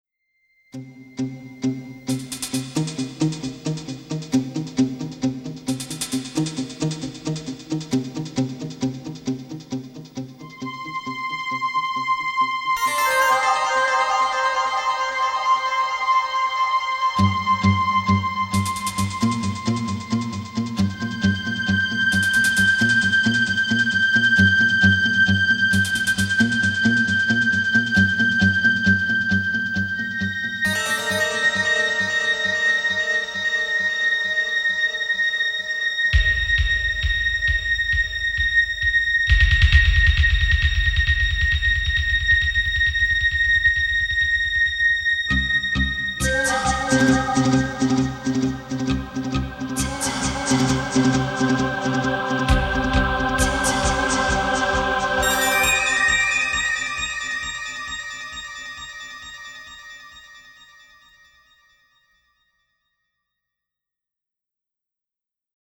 Orchestral Film Version